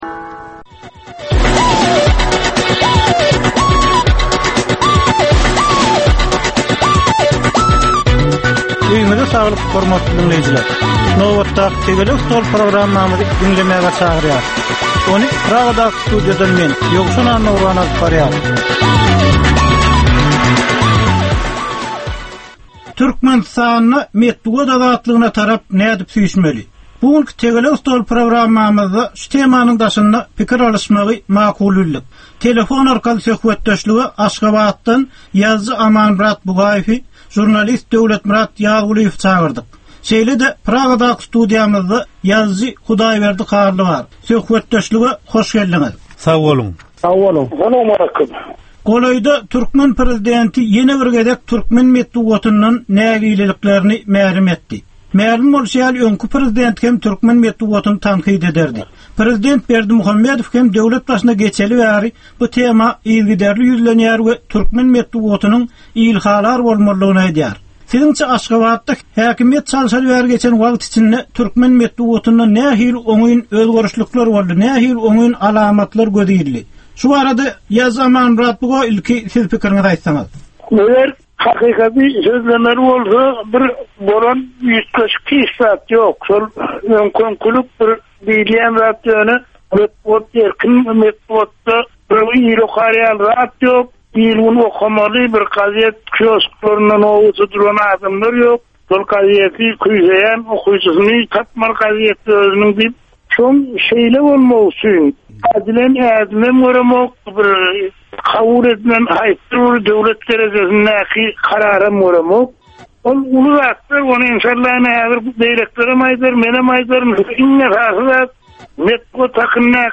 Jemgyýetçilik durmusynda bolan ýa-da bolup duran sonky möhum wakalara ýa-da problemalara bagyslanylyp taýyarlanylýan ýörite Tegelek stol diskussiýasy. 30 minutlyk bu gepleshikde syýasatçylar, analitikler we synçylar anyk meseleler boýunça öz garaýyslaryny we tekliplerini orta atýarlar.